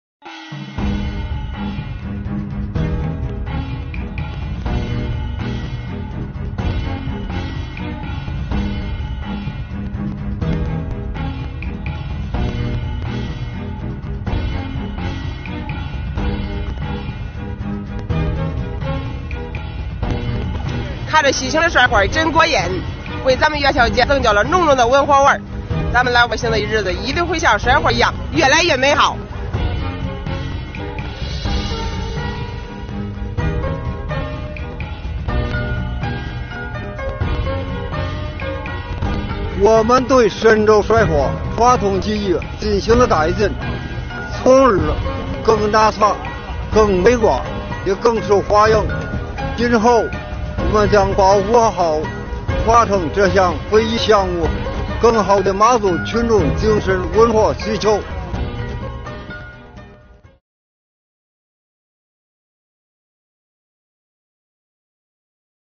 2月4日，农历正月十四，河北衡水的深州市店上村上演一年一度的迎接元宵节保留节目“甩花”，引得观众连连欢呼。